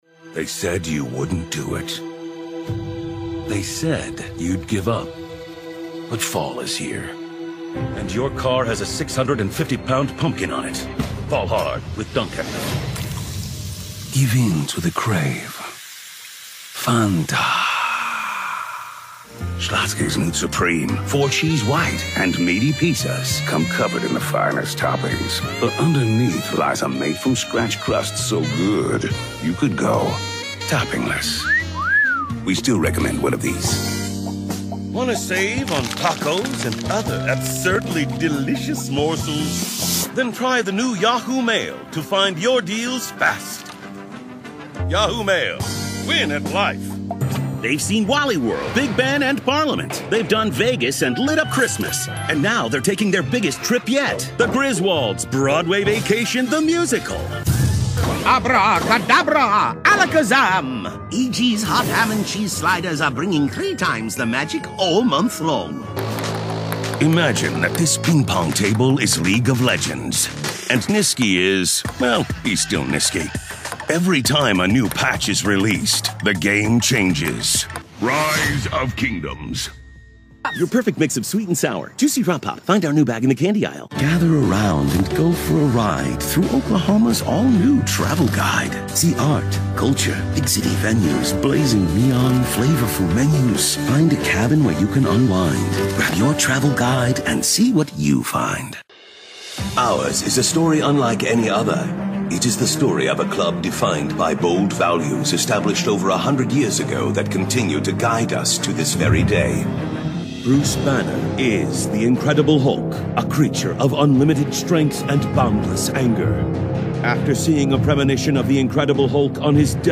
Friendly, Warm, Conversational.
Commercial